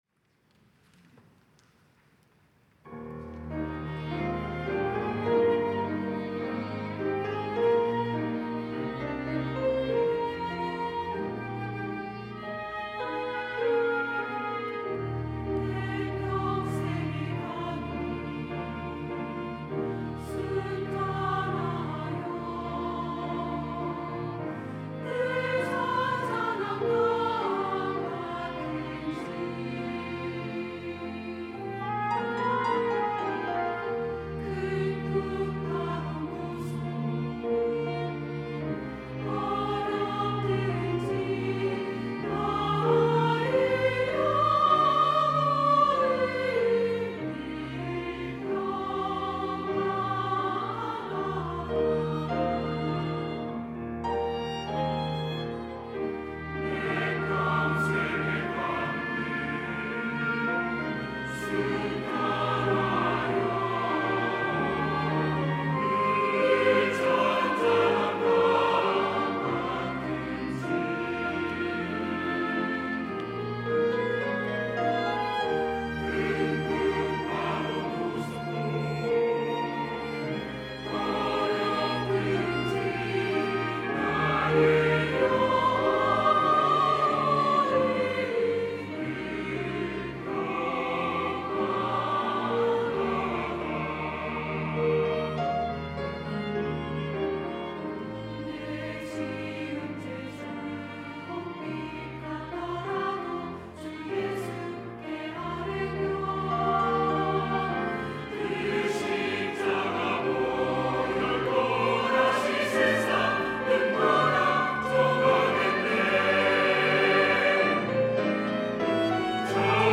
호산나(주일3부) - 내 평생에 가는 길
찬양대